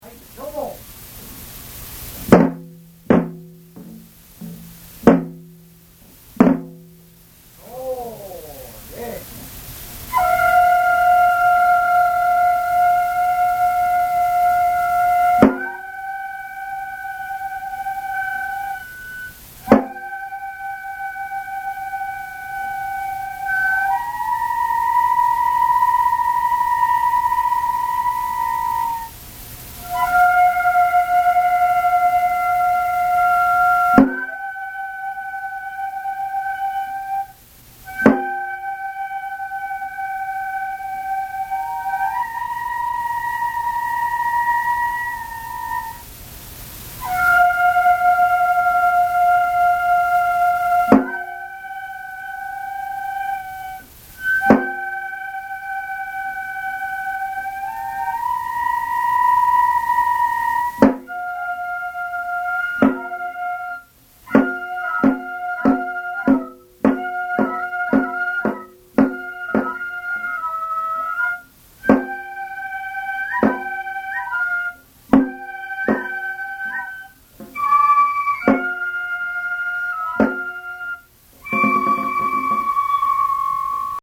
太鼓と笛のみで、子供たちはからくりを動かしている。
昭和62年11月1日　京都太秦　井進録音スタジオ